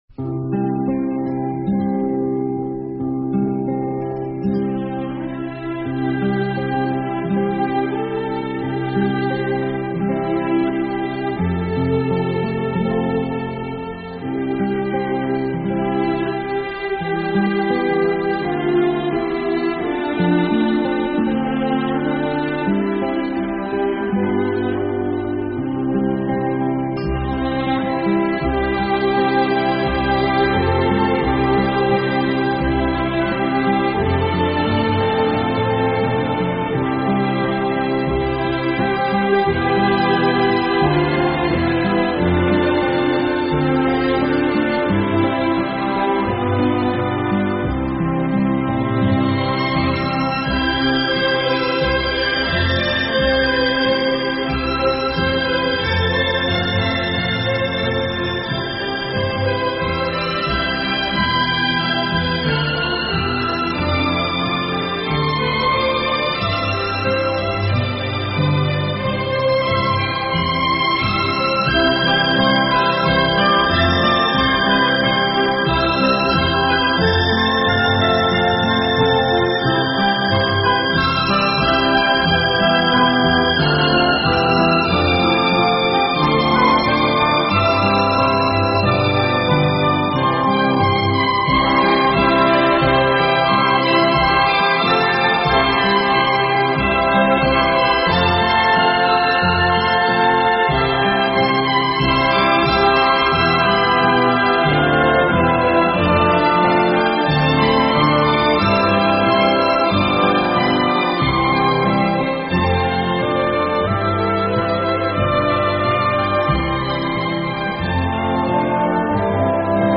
英文乡村歌曲：Micheal, row the boat 听力文件下载—在线英语听力室